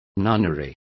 Complete with pronunciation of the translation of nunneries.